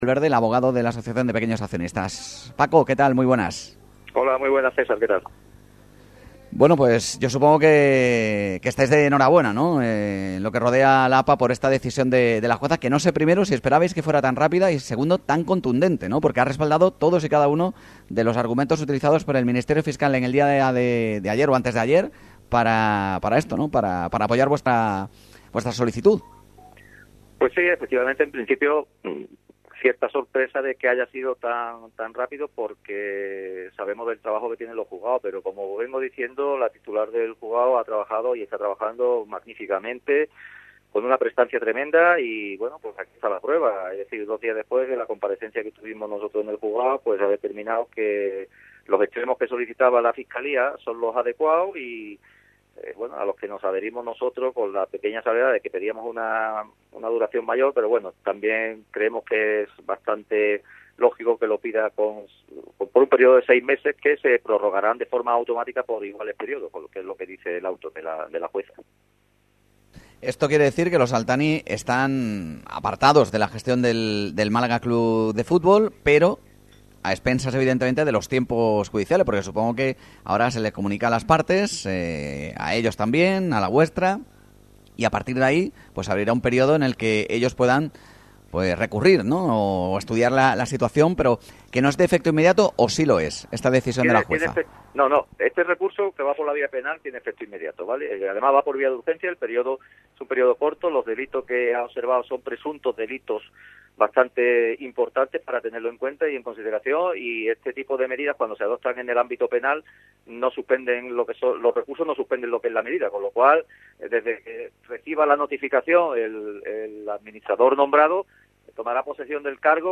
ENTREVISTA COMPLETA EN RADIO MARCA MÁLAGA